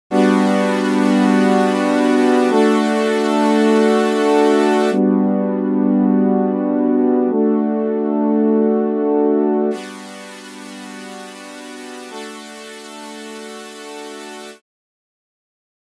例えばドラムの音でもギターの音でも、高域をカットしてやるとこもったようなヌケの悪い、鈍い音になり、低域をカットすると芯の抜けたようなか細い音になったりします。
●フィルター無し→ローパス→ハイパスのサンプル
filtersample1.mp3